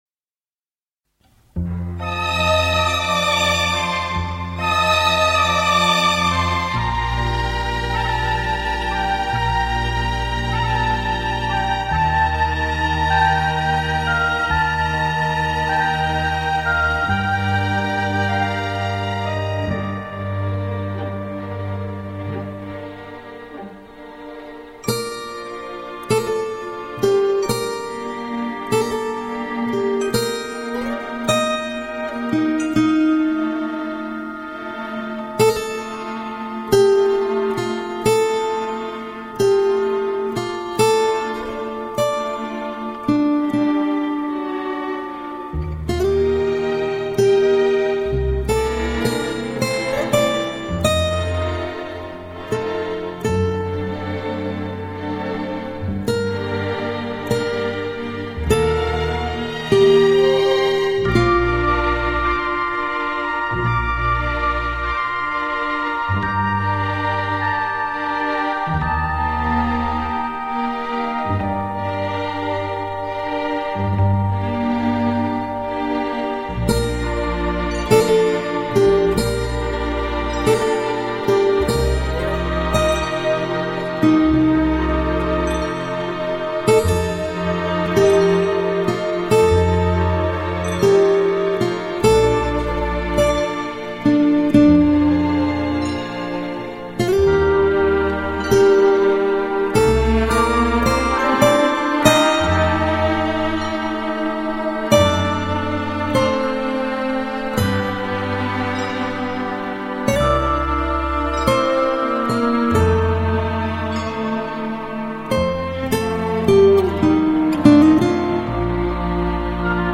0216-吉他名曲母亲教我的歌.mp3